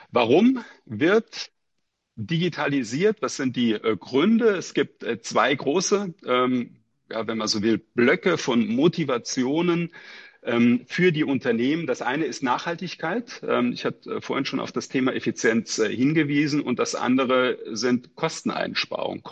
Mitschnitte der Pressekonferenz
pressekonferenz-digital-office-2025-gruende-digitalisierung-deutscher-bueros.mp3